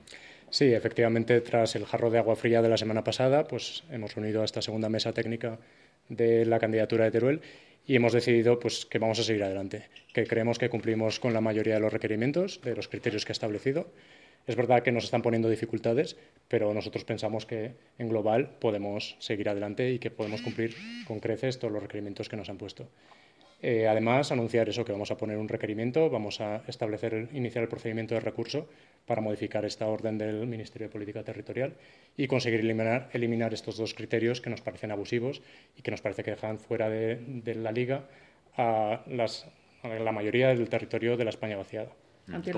El director general anuncia el recurso contra los criterios para la elección de la sede: Ramón Guirado cree que «aún hay recorrido para que la Agencia llegue a Teruel»